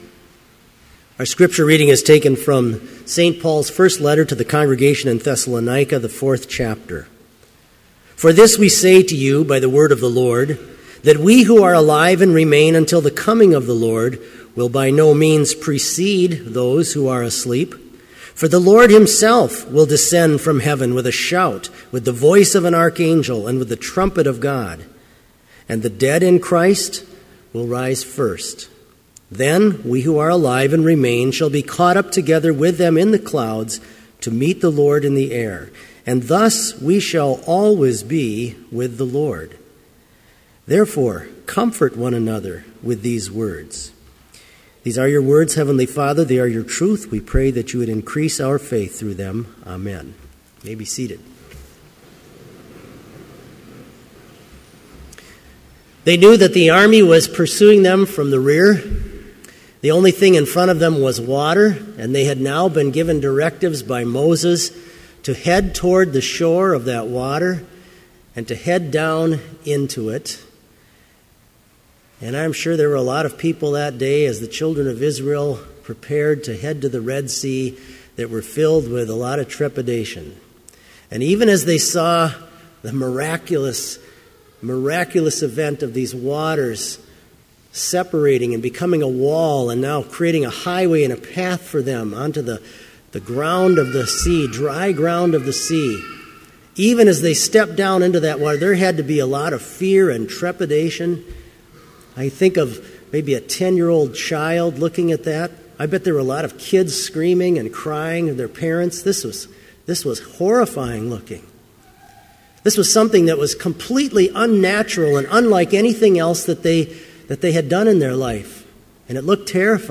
Complete service audio for Chapel - November 15, 2013